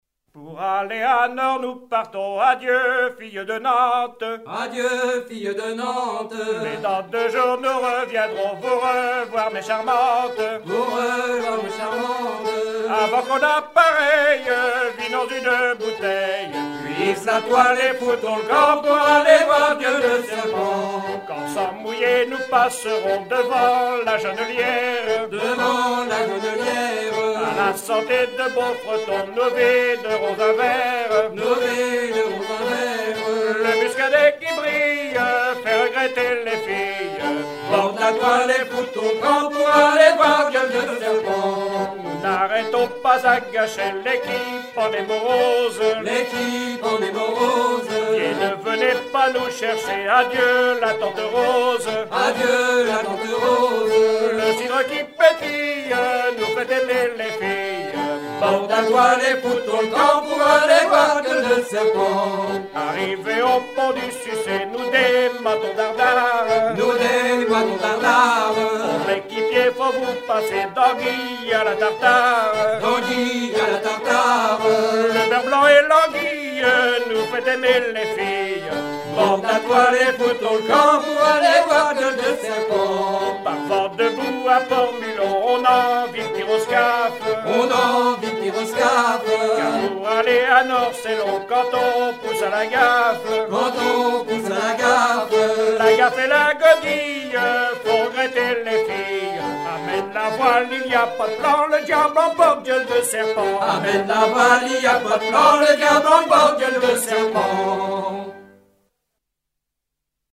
Batellerie
Pièce musicale éditée